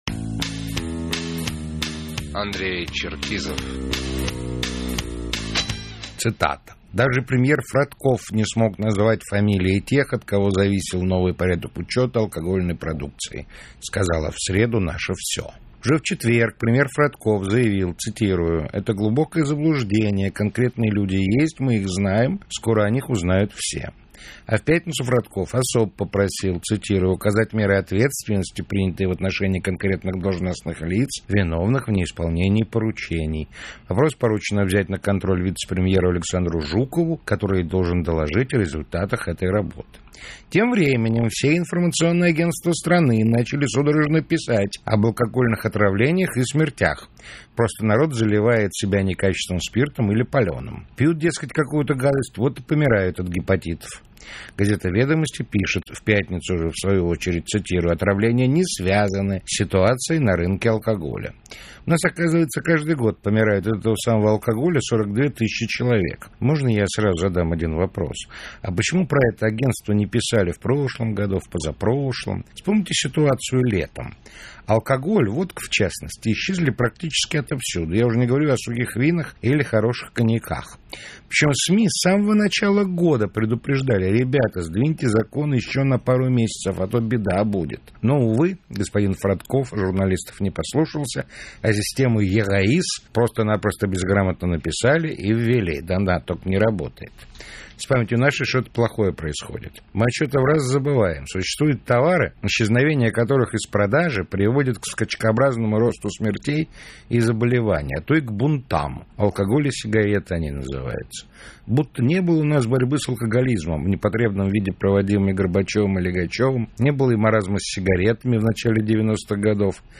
КОММЕНТАРИЙ